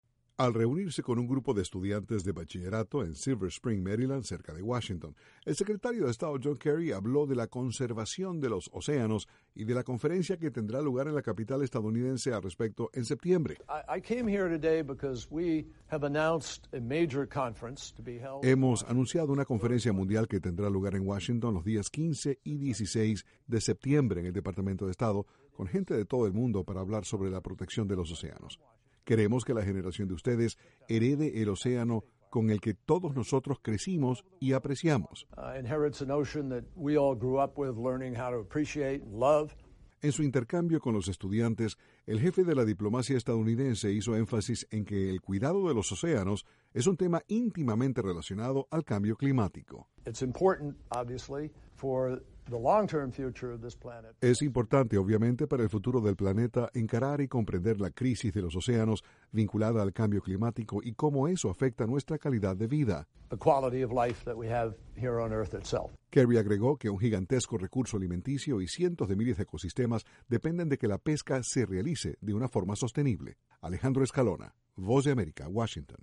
La conservación de los océanos es el tema central de una conferencia que tendrá lugar en los próximos meses, en Washington. Desde la Voz de América informa